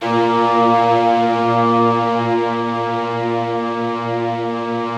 BIGORK.A#1-L.wav